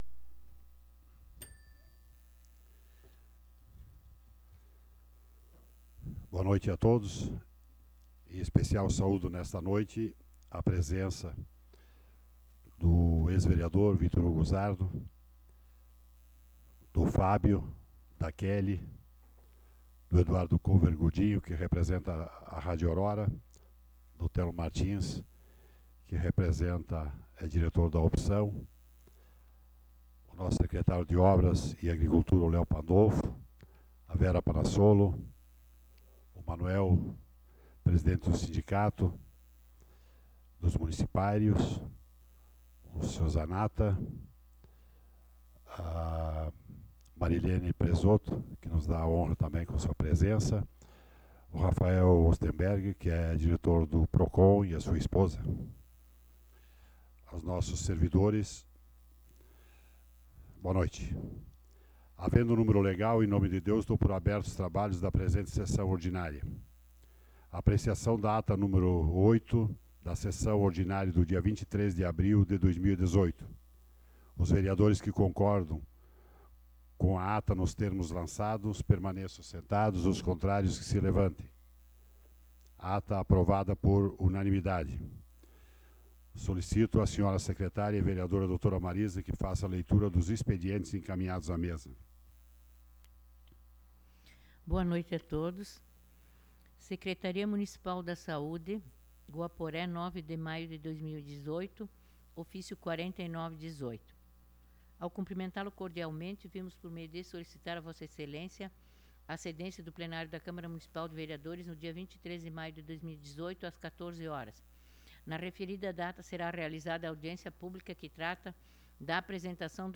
Sessão Ordinária do dia 14 de Maio de 2018